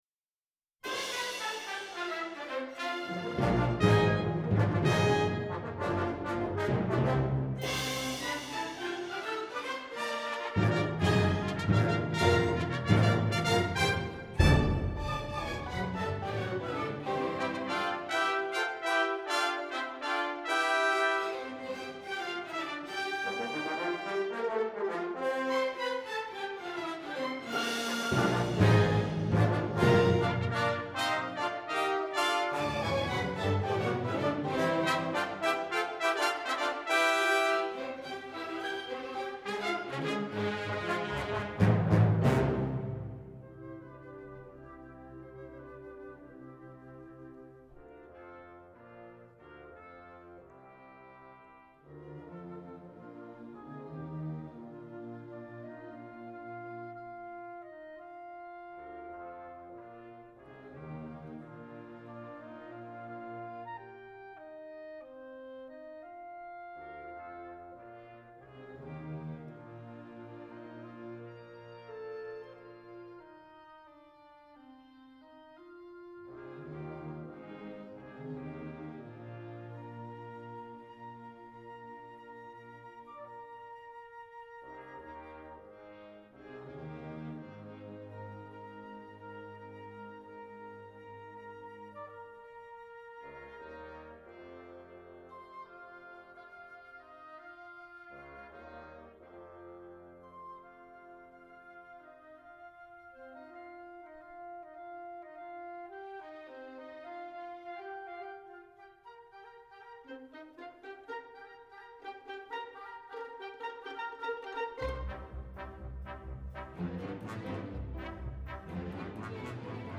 Professionally Edited Recordings